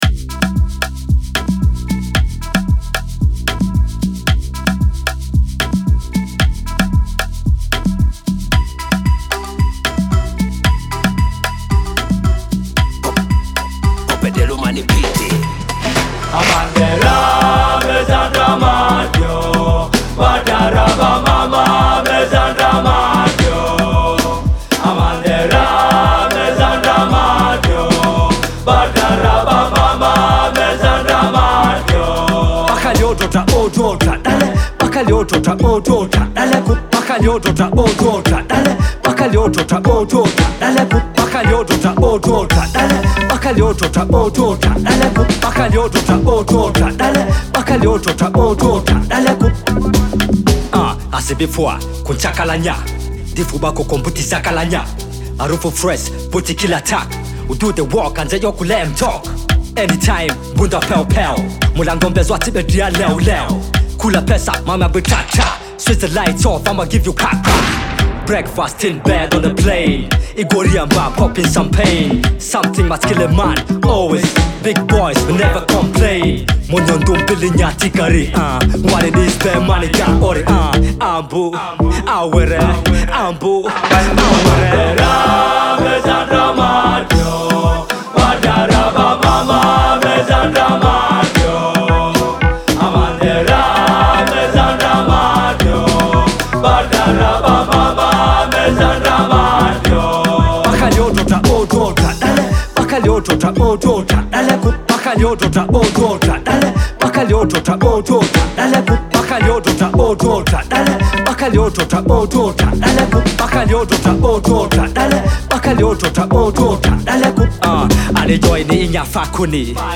Amapiano track